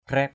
/krɛ:p/ (t.) rốp!